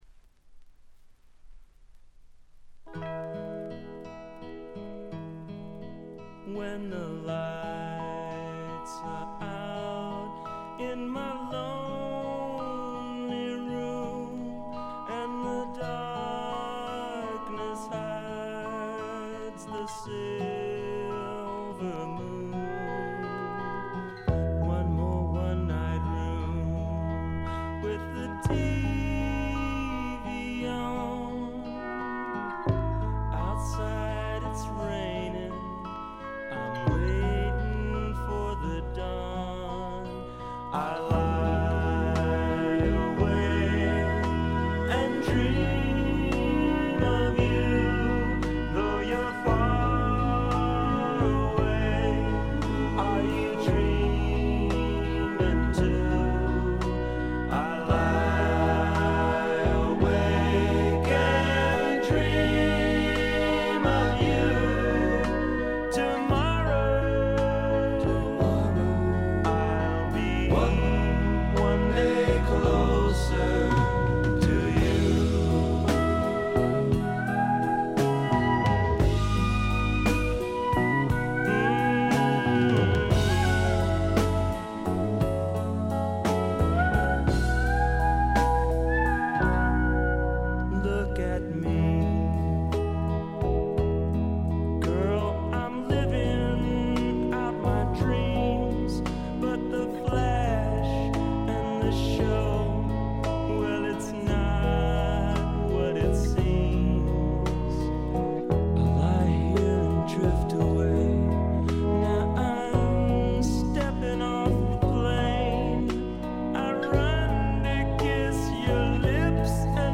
ほとんどノイズ感無し。
録音は英国ウェールズのおなじみロックフィールド・スタジオ。
ルーツ色を残した快作です。
試聴曲は現品からの取り込み音源です。